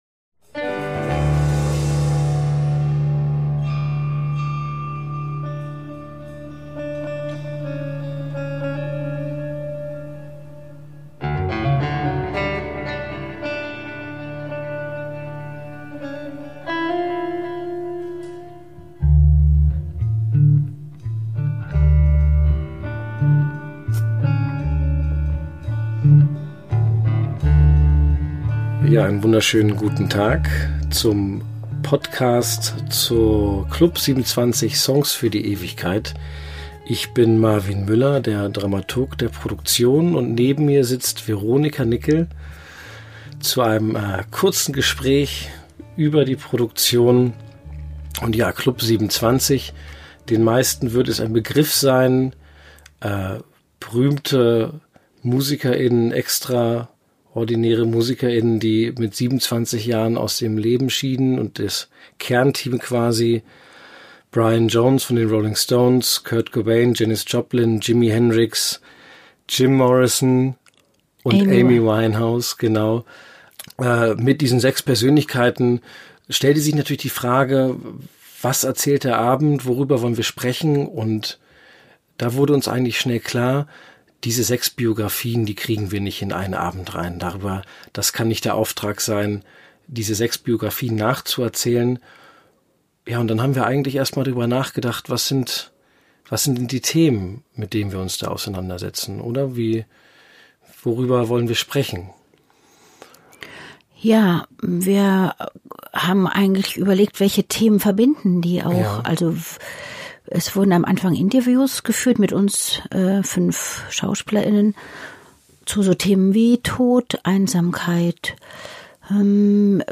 Künstler*innen und Dramaturg*innen der Produktion.